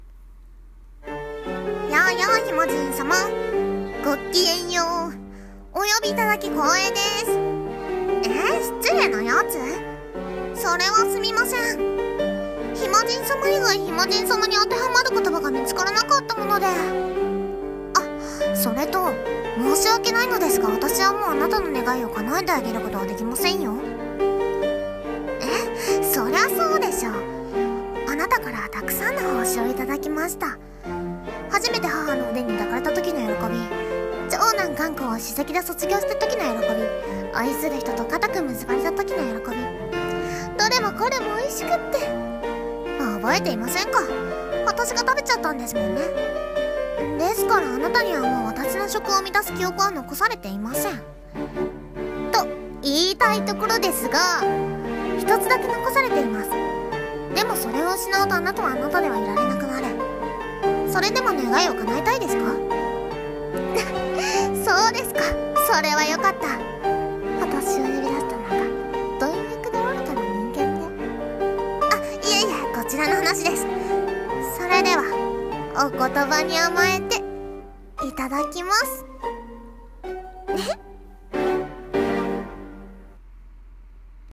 夢喰人【一人声劇